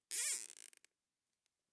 household
Zip Ties Secure 7